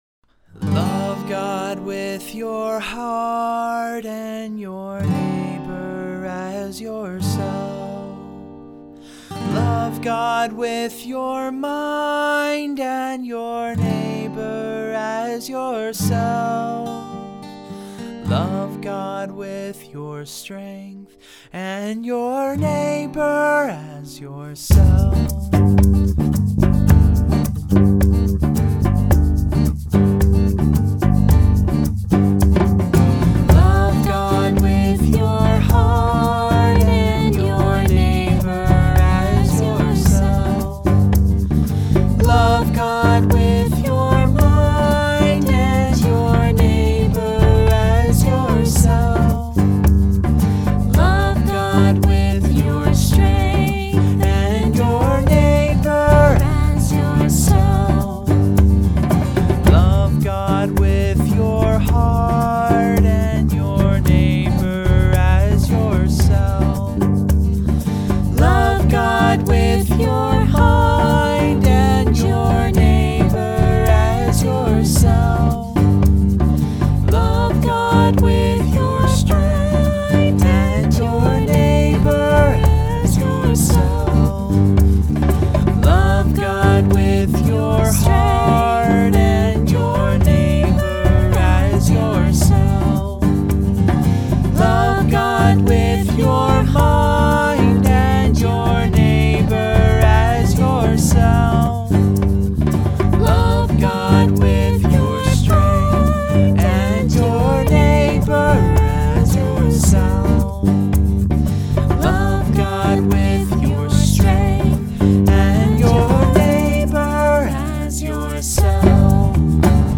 Words: from the Gospels. Music: traditional.
trombone
violin
mandolin